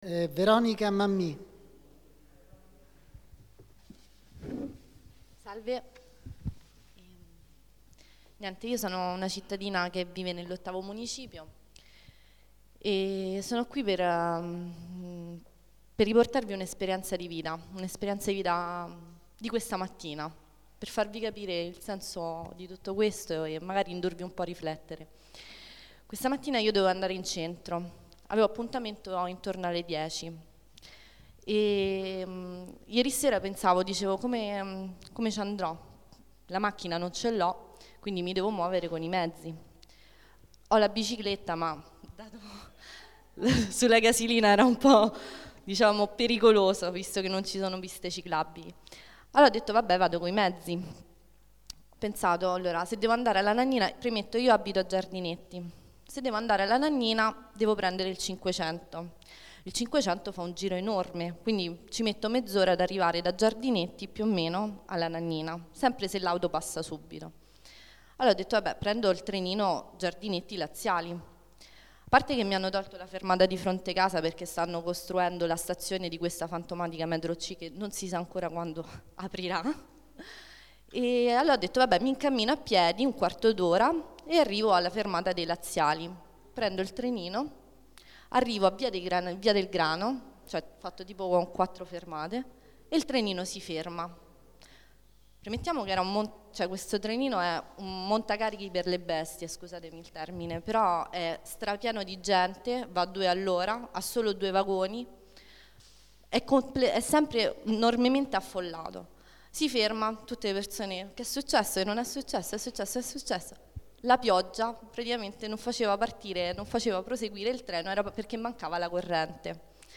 Assemblea Partecipativa TBMRegistrazione integrale dell'incontro svoltosi il 13 settembre 2012 presso la sala consiliare del Municipio VIII in Via D. Cambellotti, 11.